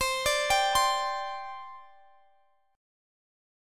Listen to Csus2 strummed